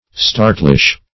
Search Result for " startlish" : The Collaborative International Dictionary of English v.0.48: Startlish \Star"tlish\ (-tl[i^]sh), a. Easily startled; apt to start; startish; skittish; -- said especially of a horse.